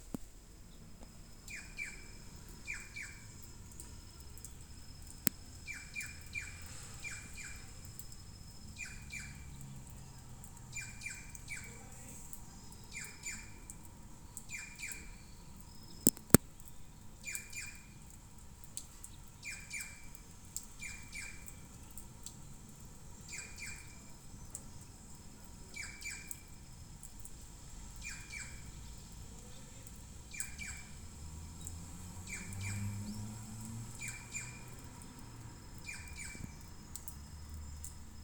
Urraca Común (Cyanocorax chrysops)
Nombre en inglés: Plush-crested Jay
Localidad o área protegida: San Miguel de Tucumán
Condición: Silvestre
Certeza: Vocalización Grabada
Urraca.mp3